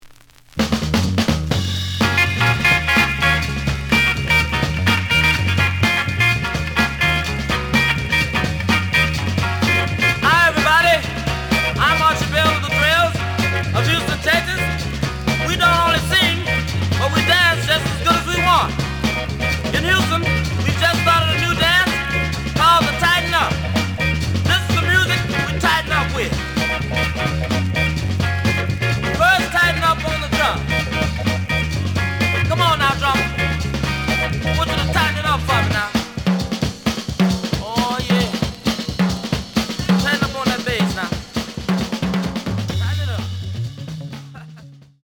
The audio sample is recorded from the actual item.
●Format: 7 inch
●Genre: Funk, 60's Funk